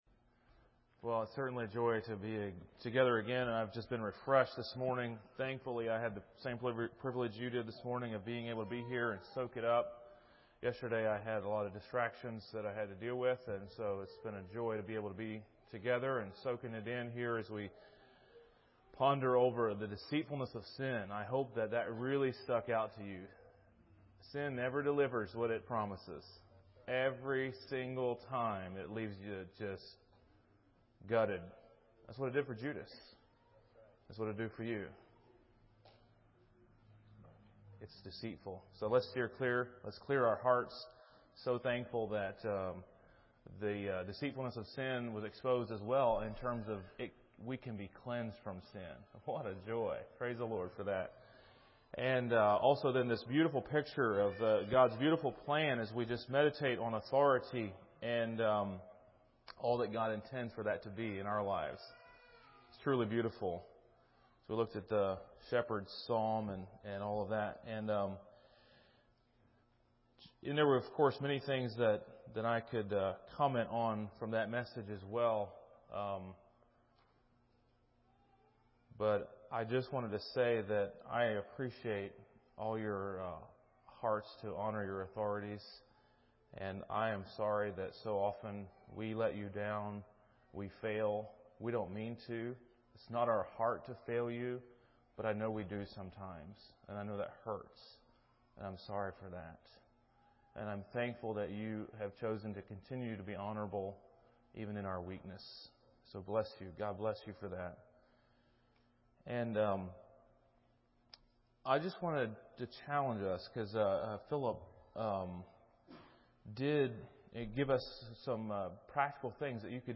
A message from the series "Bible Boot Camp 2021."